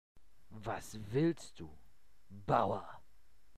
Deutsche Sprecher (m)
ich versuche soviel Verachtung wie M�glich in die Stimme zu legen, vielleicht Dunmer?